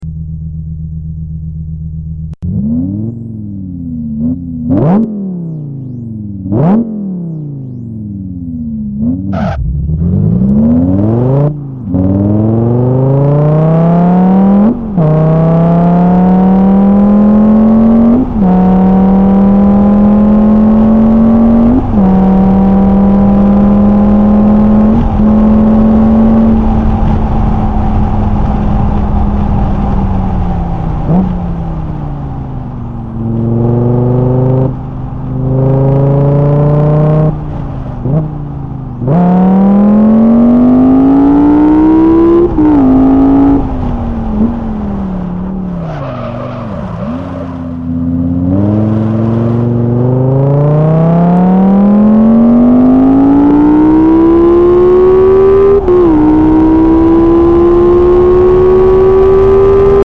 In addition I tried to make some sounds based on real vehicles.
Giallardo or Carrera GT.mp3 - 938.4 KB - 1222 views
I listened to the 911 and Gallardo sounds. Actually quite comparable to the real counterparts.